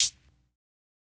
Closed Hats
HIHAT_DETER.wav